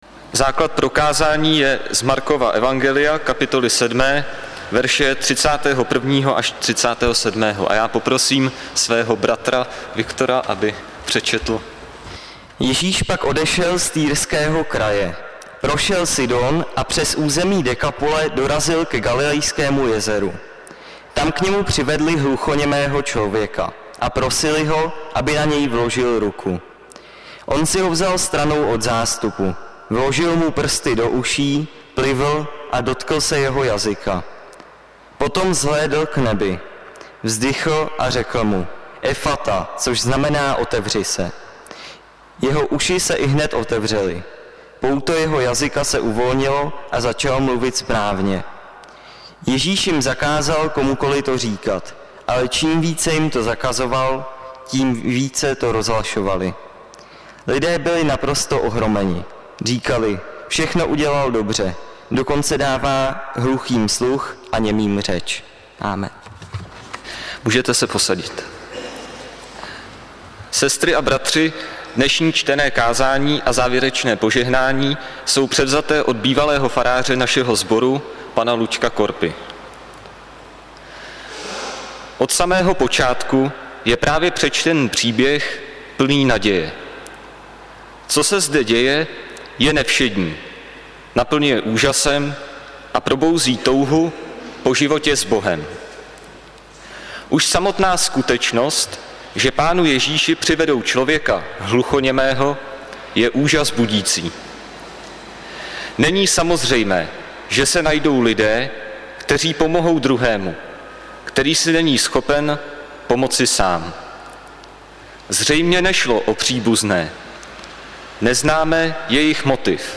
audio kázání na text z Markova evangelia, 7. kapitoly 31 – 37 zde